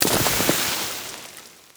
Divergent / mods / JSRS Sound Mod / gamedata / sounds / weapons / _boom / mono / dirt3.ogg
dirt3.ogg